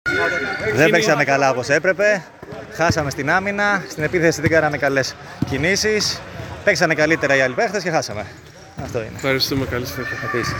GAME INTERVIEWS: